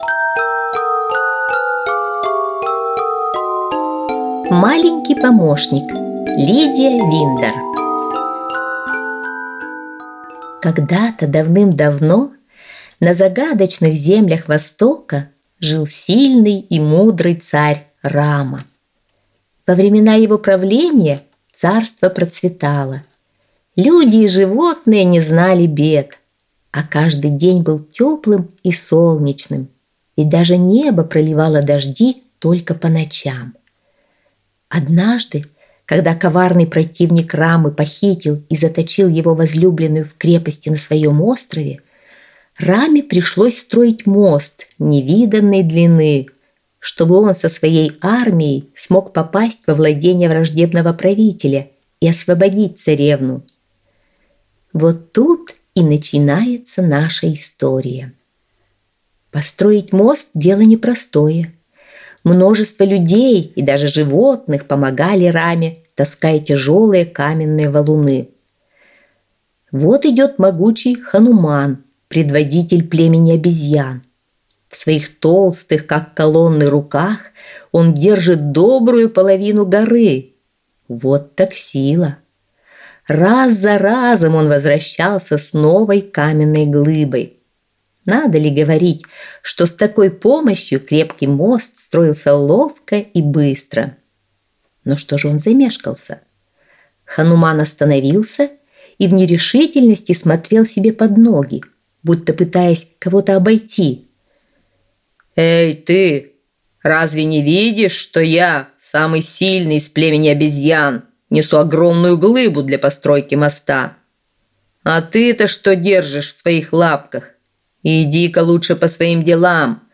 Аудиосказка «Маленький помощник»